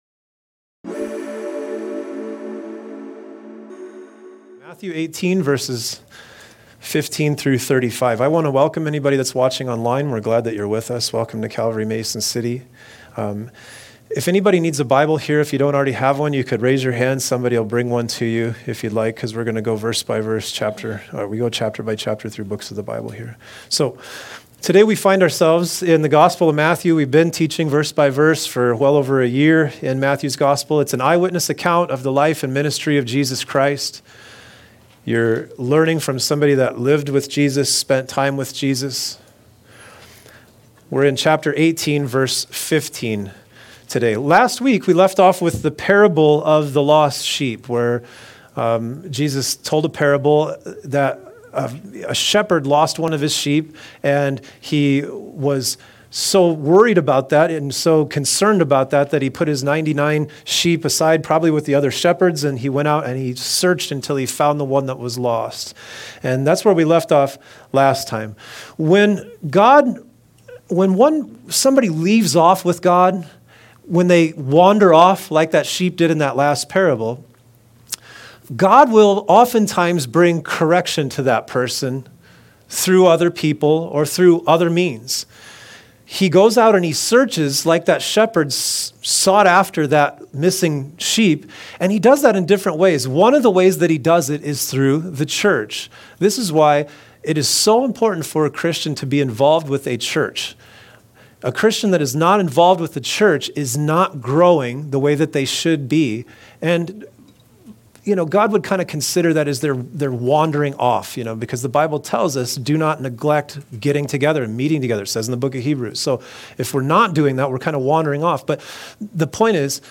A message from the series "The Gospel of Matthew."